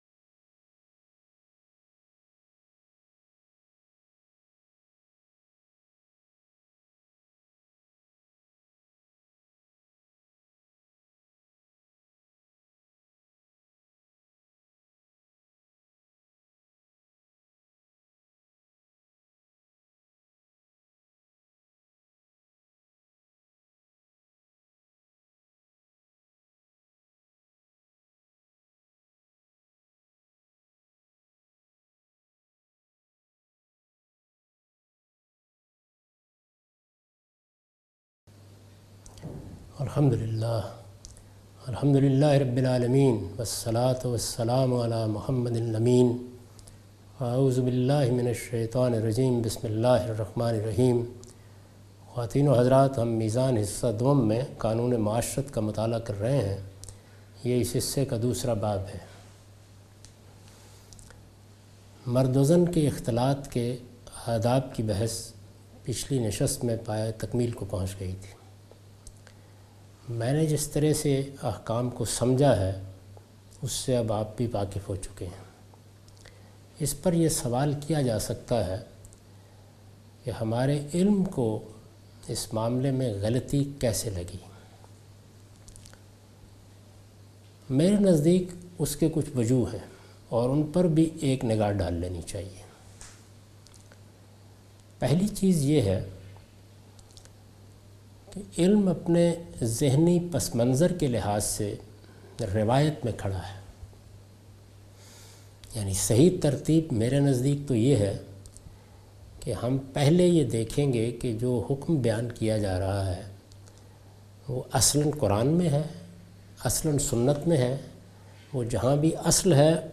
A comprehensive course on Islam, wherein Javed Ahmad Ghamidi teaches his book ‘Meezan’.
In this lecture he teaches norms of gender interaction in Islam.